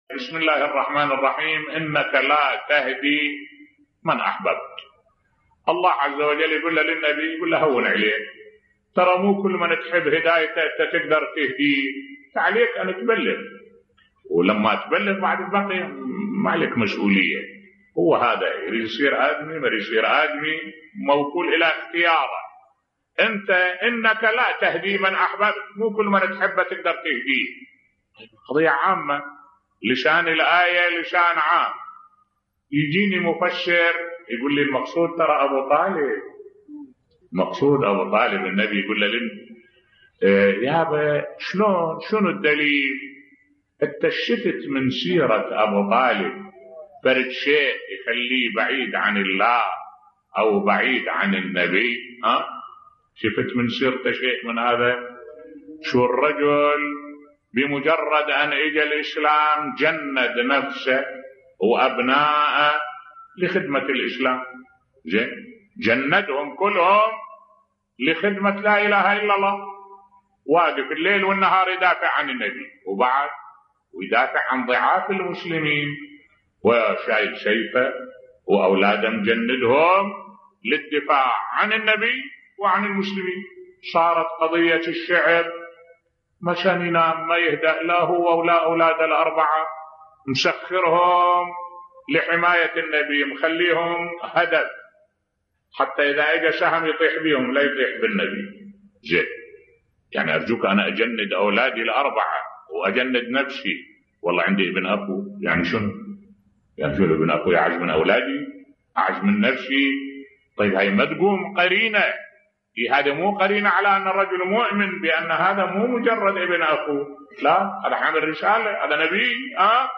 ملف صوتی إيمان أبو طالب عليه السلام و حقيقة أقوال المخالفين بصوت الشيخ الدكتور أحمد الوائلي